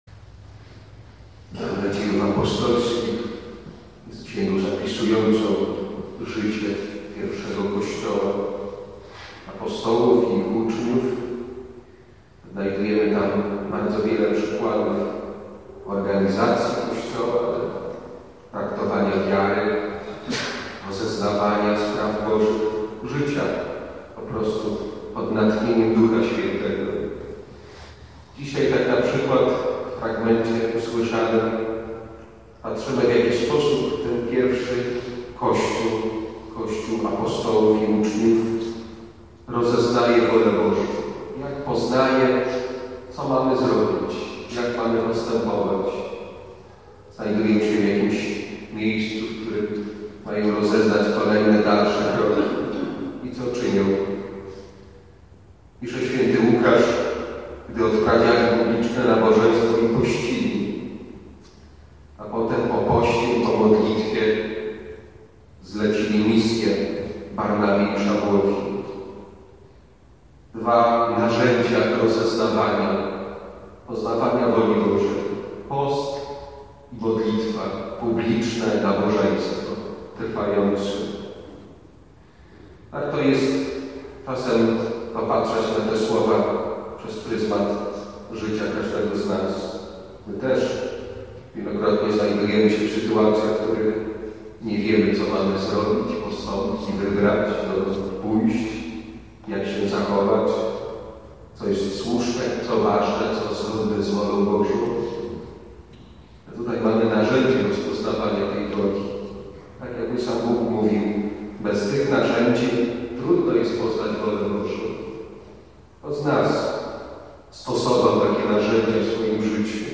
Działy: Czytania liturgiczne, Kazania